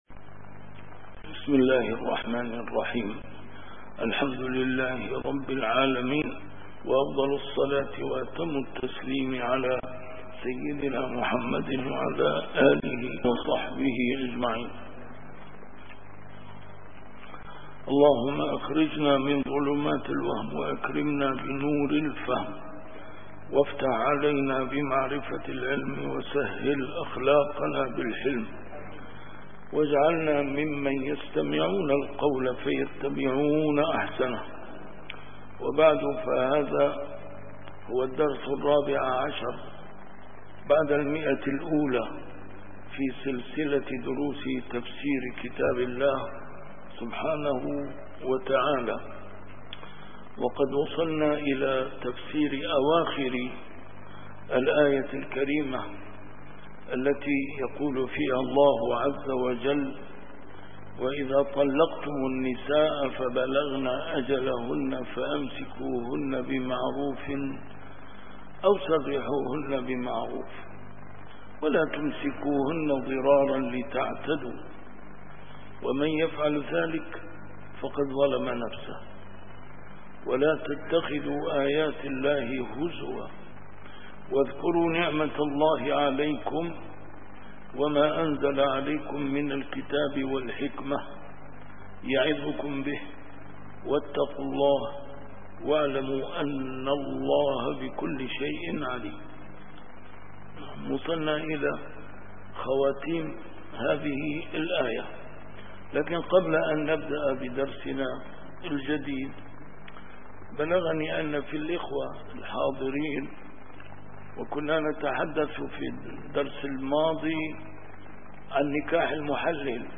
A MARTYR SCHOLAR: IMAM MUHAMMAD SAEED RAMADAN AL-BOUTI - الدروس العلمية - تفسير القرآن الكريم - تفسير القرآن الكريم / الدرس الرابع عشر بعد المائة: سورة البقرة: الآية 231-233